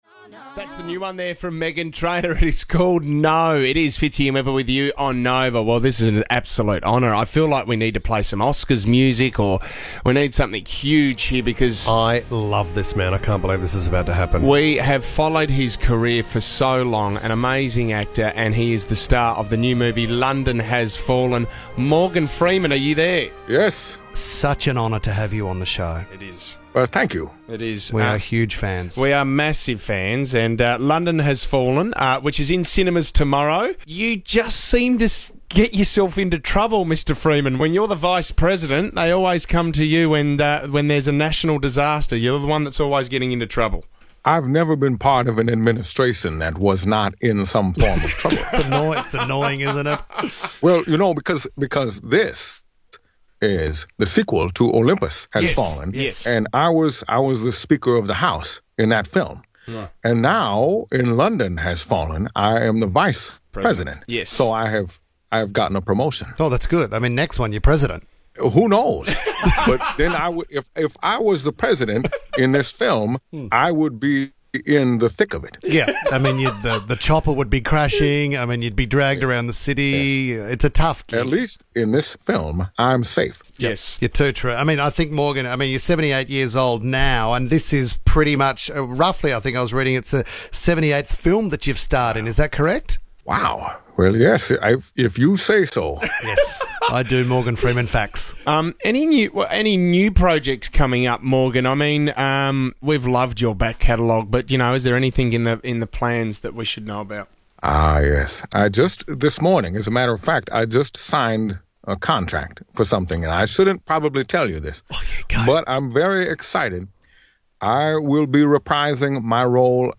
The bit was simple – just be Morgan Freeman for a few minutes.
Morgan Freeman impersonator, Radio prank